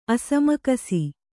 ♪ asamakasi